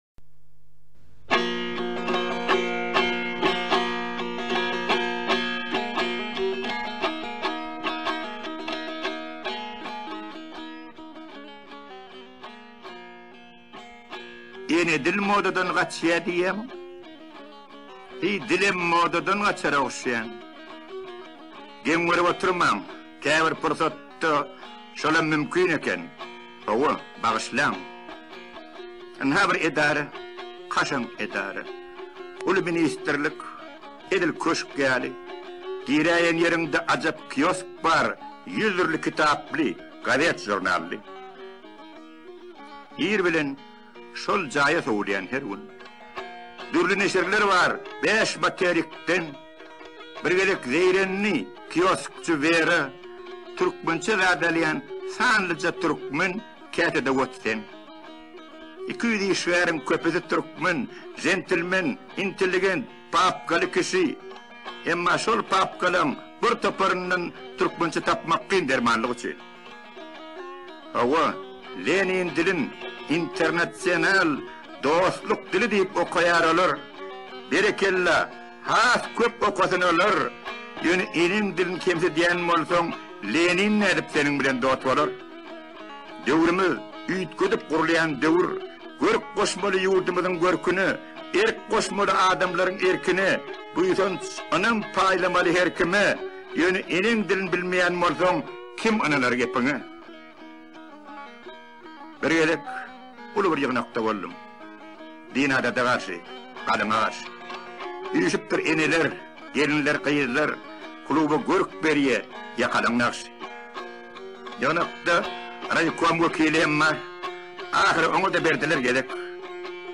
دکلمه شعر « انه دیل »